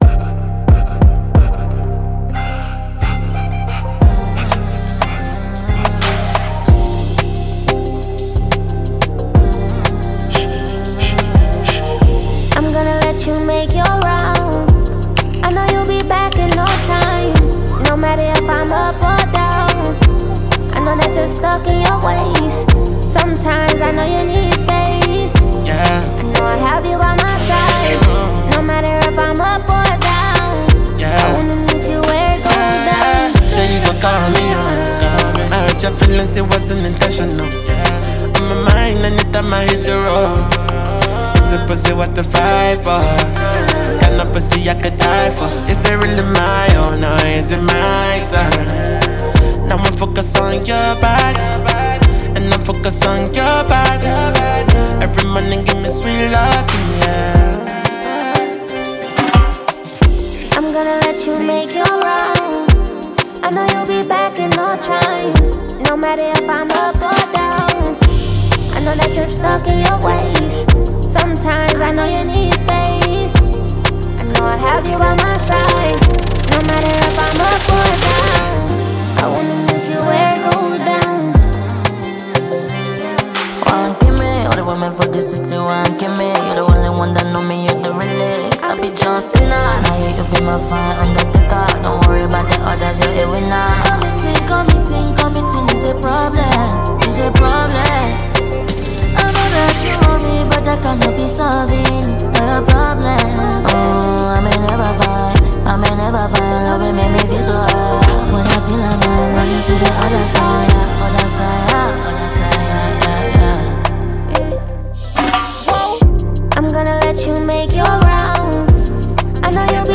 Talented American rapper and music singer